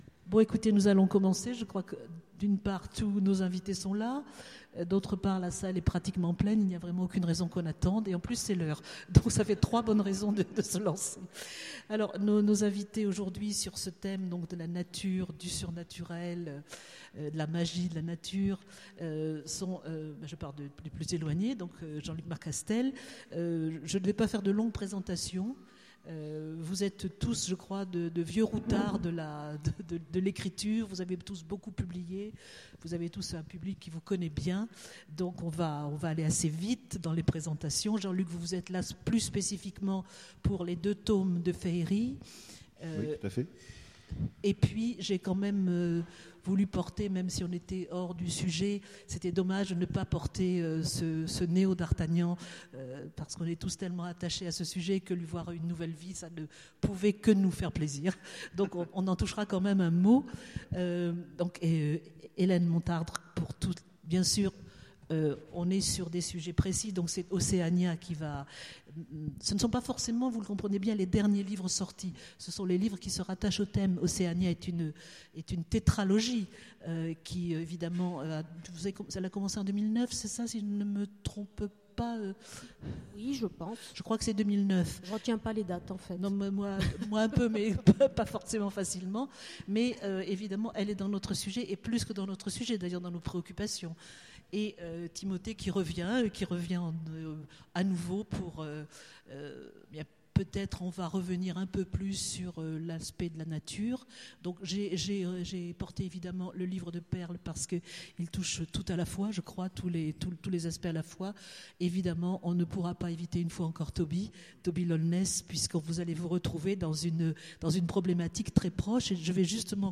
Étonnants Voyageurs 2015 : Conférence Nature, magie et surnaturel